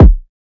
Kick (Really).wav